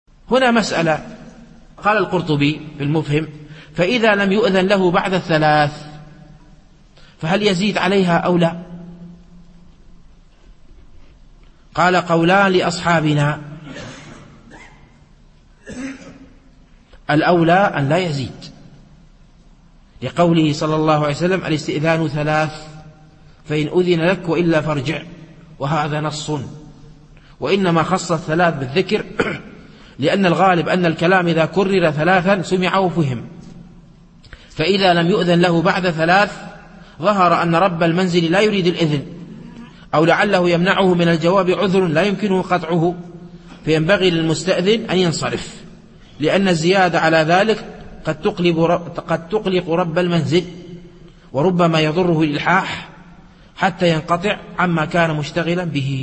الألبوم: شبكة بينونة للعلوم الشرعية المدة: 0:56 دقائق (259.09 ك.بايت) التنسيق: MP3 Mono 22kHz 32Kbps (VBR)